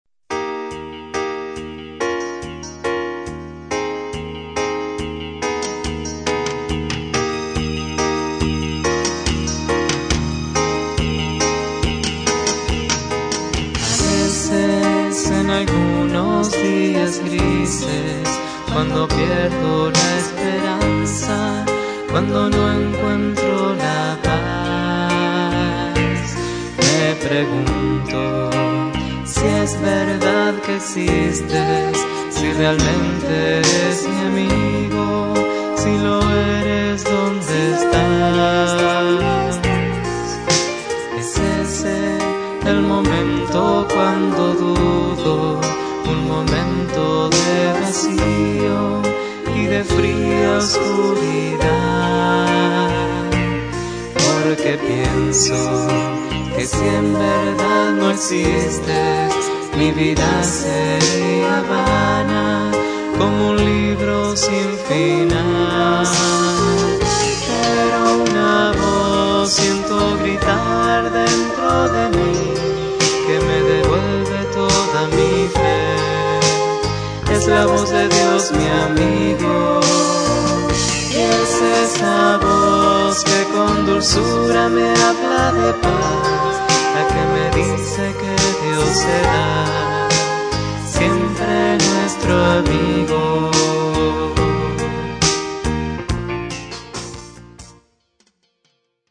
teclados, guitarra
Canciones a tres voces